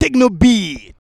TEKNO BEAT.wav